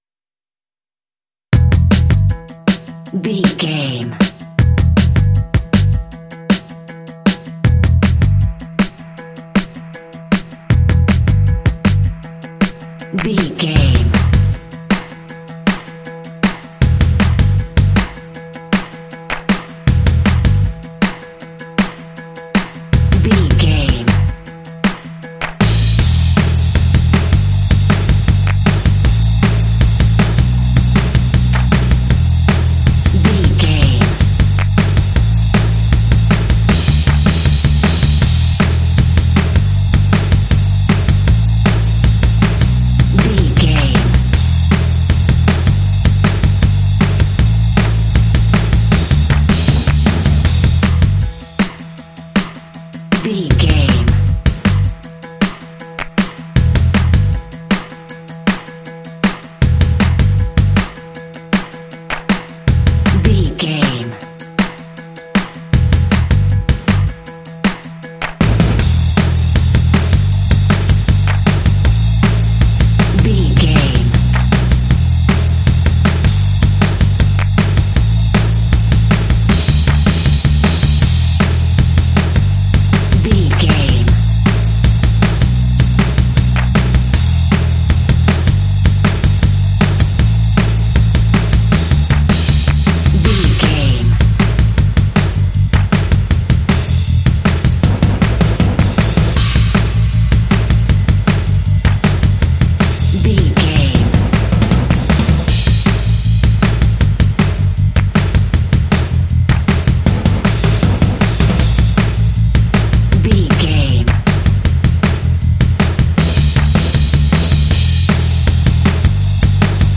Aeolian/Minor
Fast
driving
intense
powerful
energetic
bass guitar
drums
heavy metal
symphonic rock